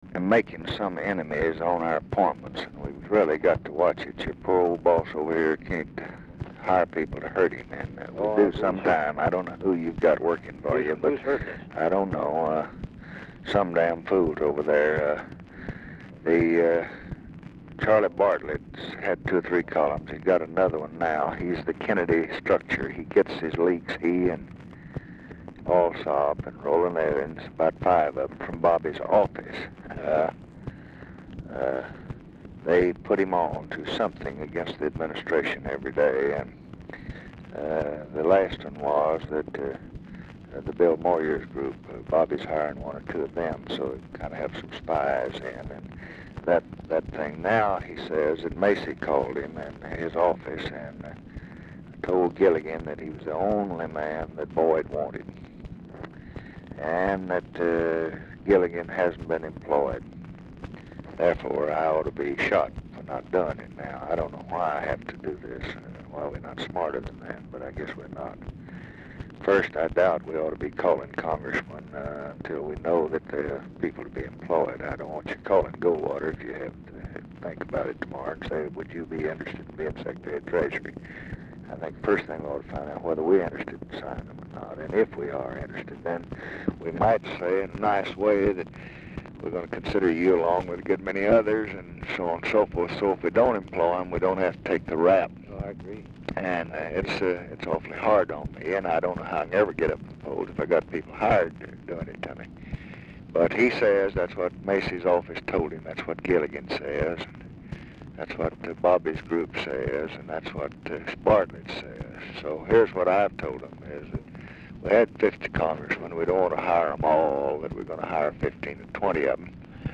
Telephone conversation # 11510, sound recording, LBJ and JOHN MACY, 2/3/1967, 10:20AM | Discover LBJ
RECORDING STARTS AFTER CONVERSATION HAS BEGUN
Format Dictation belt
Location Of Speaker 1 Mansion, White House, Washington, DC
Specific Item Type Telephone conversation